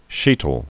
(chētl)